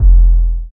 MexikoDro808 (2).wav